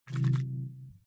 open-panel-side.mp3